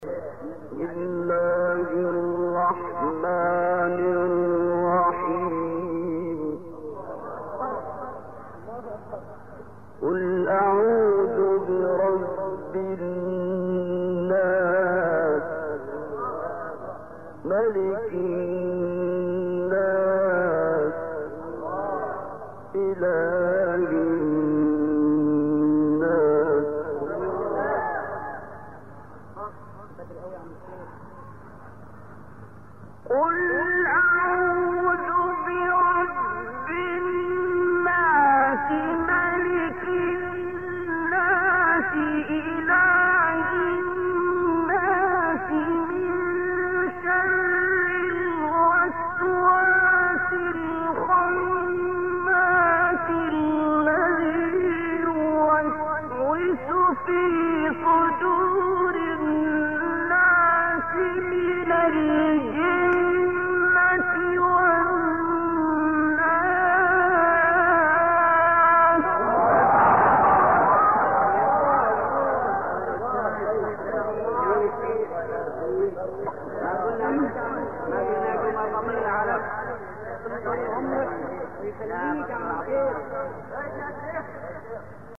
تلاوت استاد عبدالباسط